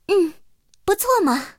野蜂强化语音.OGG